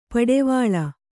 ♪ paḍevāḷa